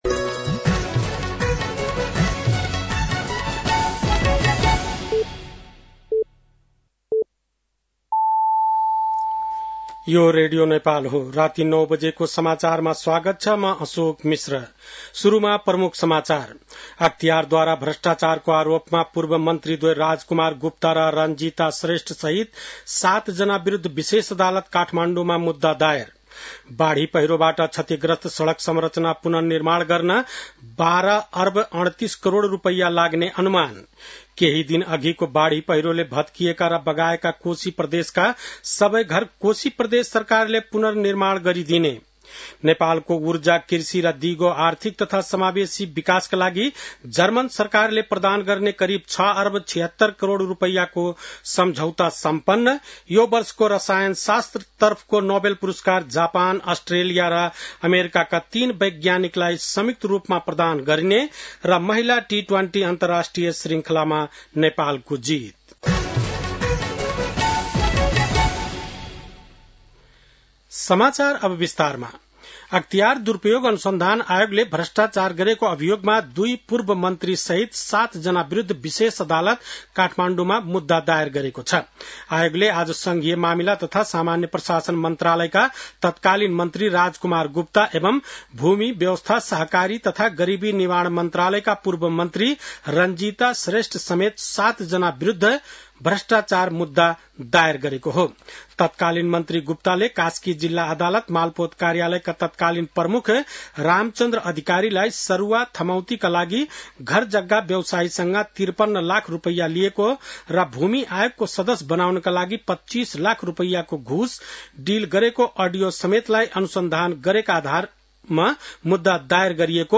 बेलुकी ८ बजेको अङ्ग्रेजी समाचार : २२ असोज , २०८२